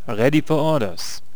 archer_select1.wav